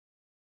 silence.ogg